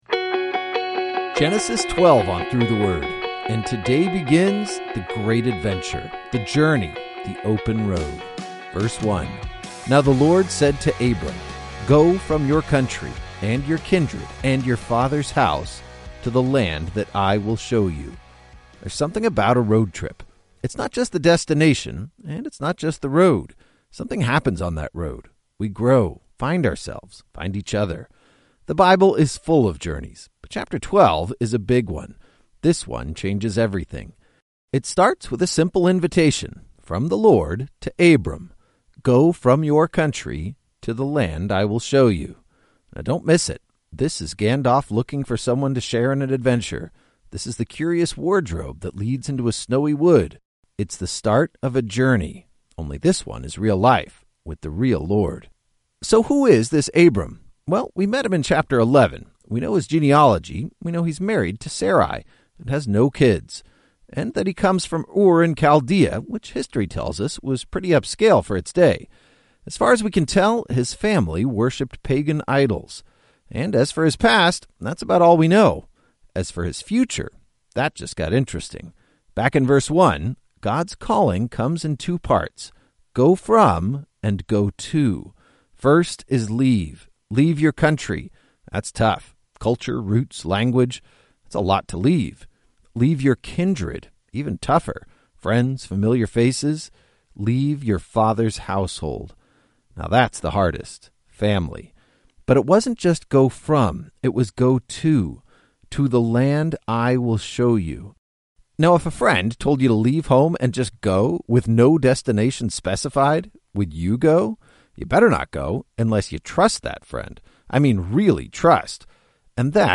19 Journeys is a daily audio guide to the entire Bible, one chapter at a time.